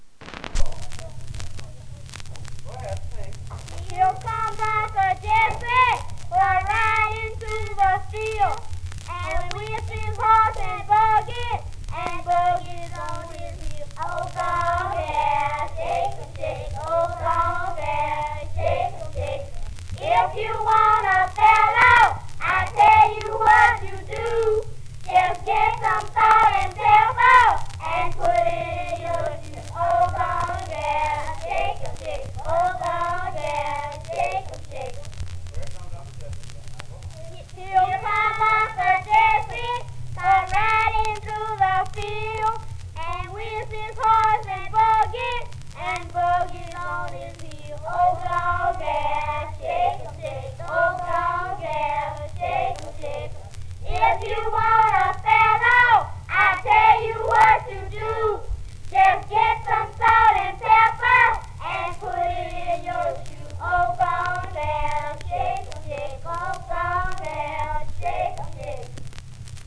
戀歌 (Sweetheart Song)
Many songs had corresponding games. The song in this clip, "Here Comes Uncle Jesse," is played for a ring game in which one girl stands in the middle. The other girls skip or march around her, and when they sing "come on gal," they stop and the girl in the center "shakes."
sh_kidsongs_sweethrt_2.wav